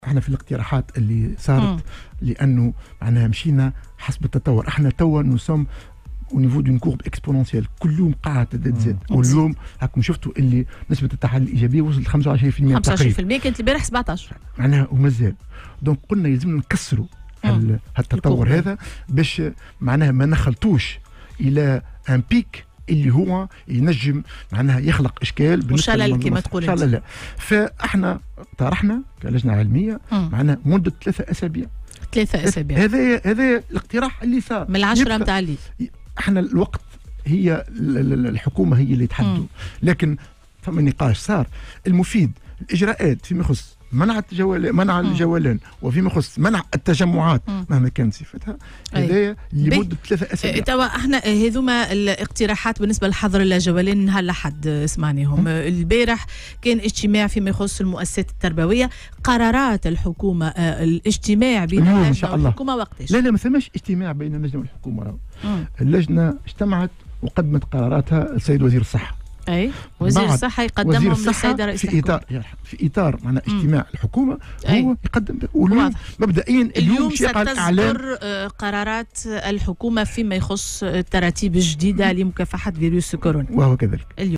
وأضاف في مداخلة له اليوم على "الجوهرة أف أم" أن اللجنة كانت قد تقدّمت، يوم الأحد الماضي، بمقترحات من بينها فرض الجولان الليلي لمدّة 3 أسابيع ومنع التجمعات بكل أنواعها.